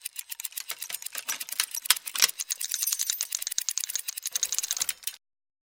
На этой странице собраны звуки шестерёнок разного типа: от мягкого перекатывания до резкого металлического скрежета.
Механический гул вращающихся шестеренок